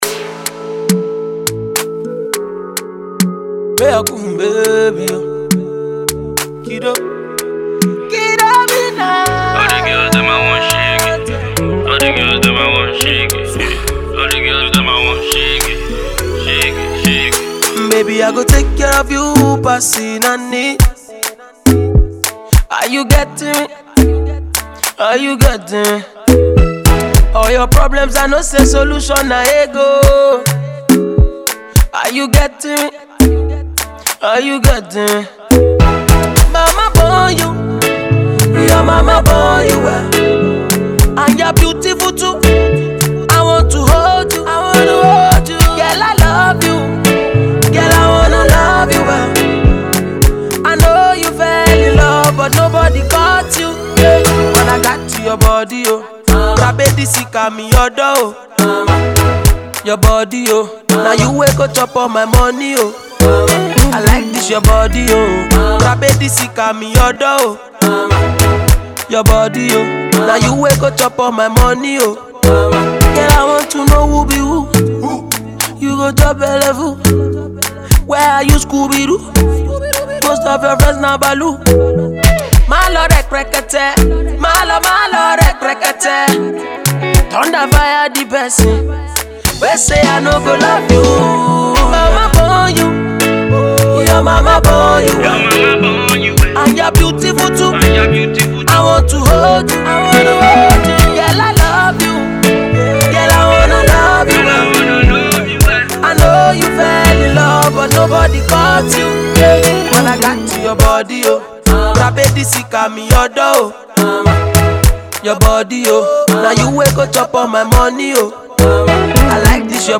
This groovy number